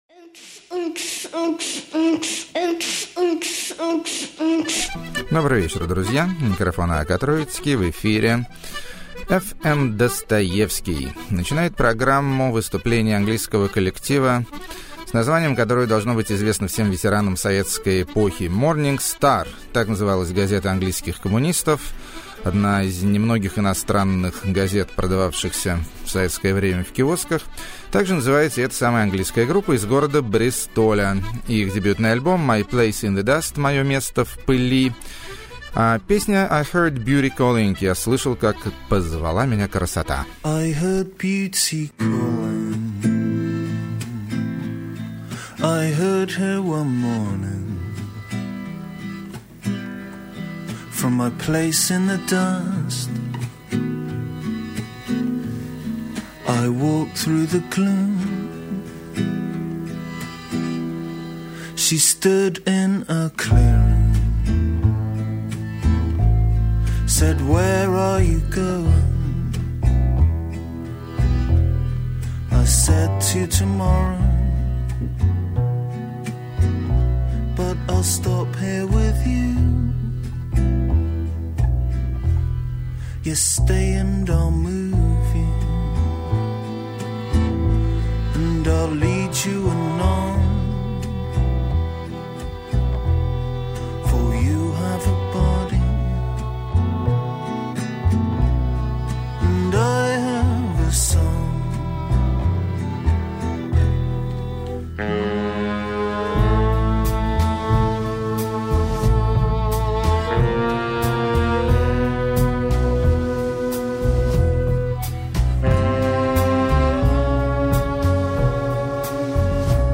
Галимый Шансон С Занятным Арабским Влиянием.
Industrial Down Tempo?] 9.
Бардачный Гараж-фанк.